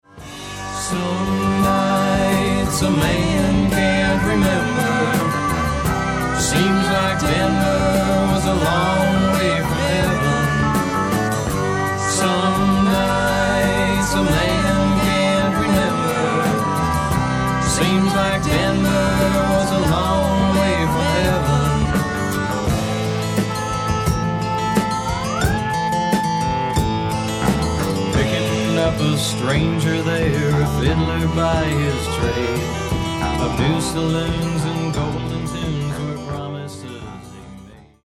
AMERICAN ROCK